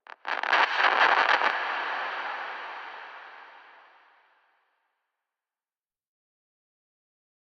Minecraft Version Minecraft Version latest Latest Release | Latest Snapshot latest / assets / minecraft / sounds / ambient / nether / warped_forest / addition2.ogg Compare With Compare With Latest Release | Latest Snapshot